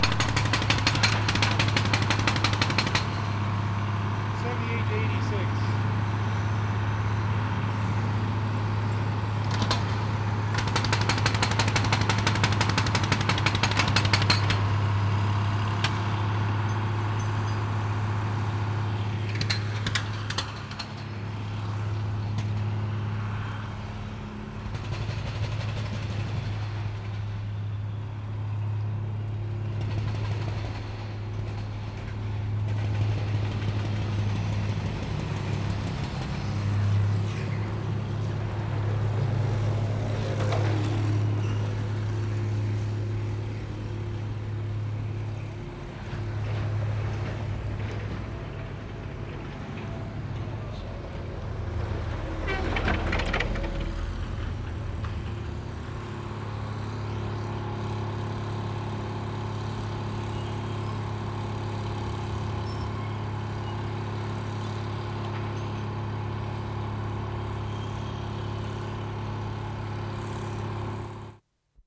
JACKHAMMER, COMPRESSOR 1'10"
2. Powell and Seymour St., Eaton's demolition site. This jackhammer is not that noisy. It sounds as if microphones get swung into different directions throughout.